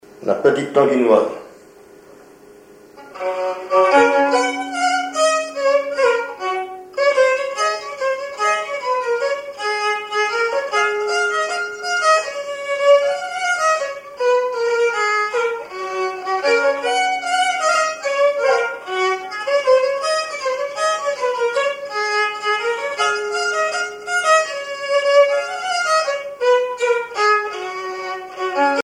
violoneux, violon
Pièce musicale inédite